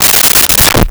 Umbrella Opened 02
Umbrella Opened 02.wav